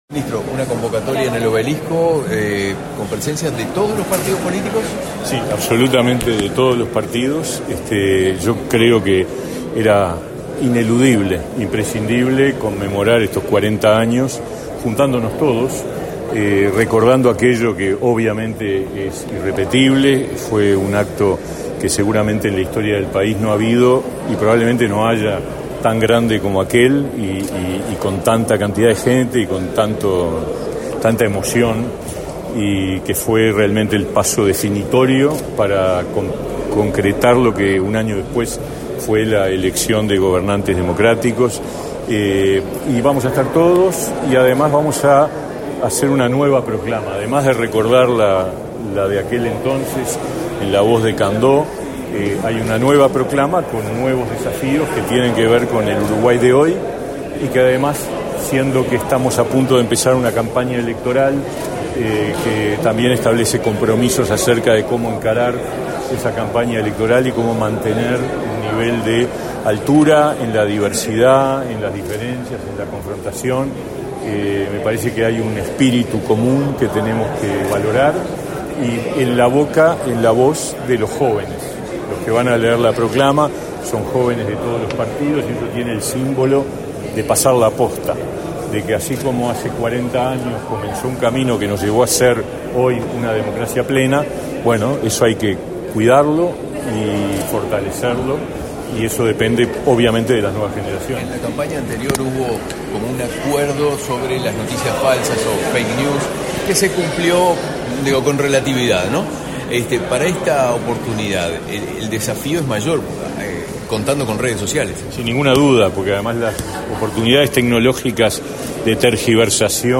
Declaraciones a la prensa del ministro del MTSS, Pablo Mieres
Declaraciones a la prensa del ministro del MTSS, Pablo Mieres 27/11/2023 Compartir Facebook Twitter Copiar enlace WhatsApp LinkedIn Con la presencia del presidente de la República, Luis Lacalle Pou, el Ministerio de Trabajo y Seguridad Social (MTSS) presentó, este 27 de noviembre, datos sobre la protección laboral a los trabajadores. Tras el evento, el ministro realizó declaraciones a la prensa.